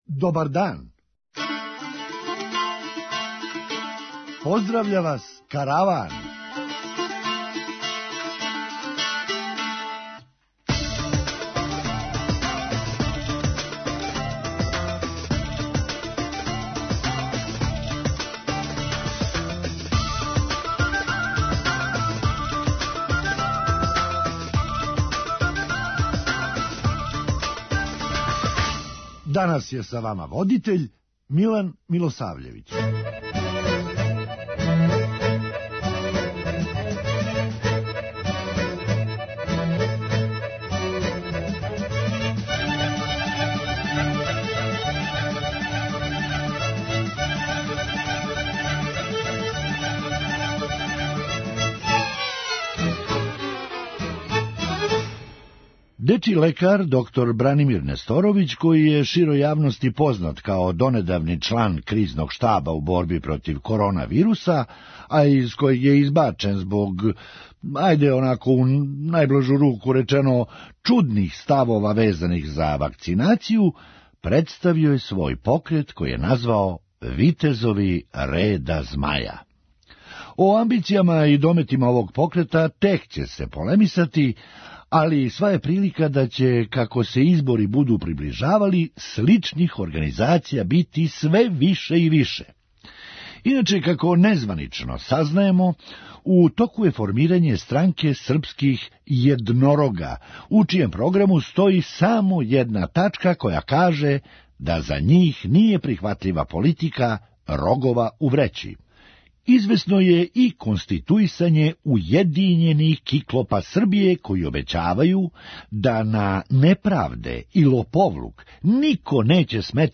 Хумористичка емисија
А ко је тако нешто дозволио, вероватно ће показати истрага. преузми : 8.97 MB Караван Autor: Забавна редакција Радио Бeограда 1 Караван се креће ка својој дестинацији већ више од 50 година, увек добро натоварен актуелним хумором и изворним народним песмама.